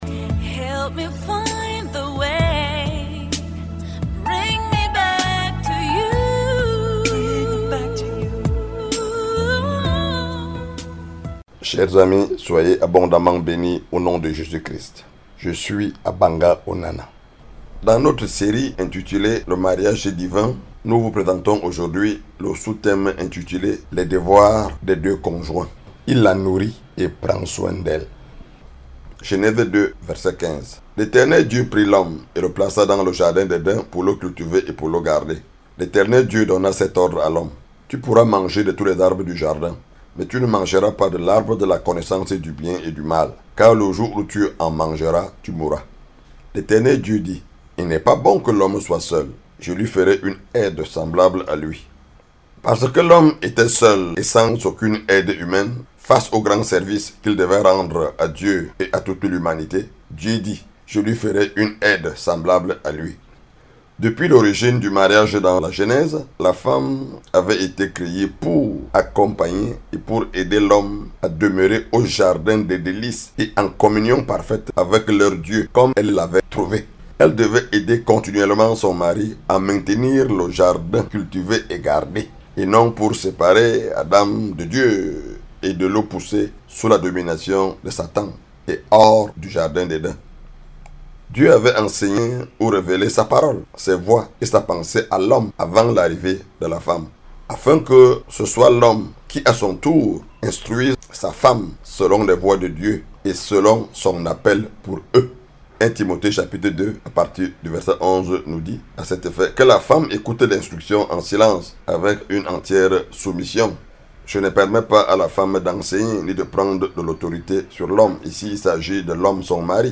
Cet enseignement fait partie de la collection "le Mariage Divin".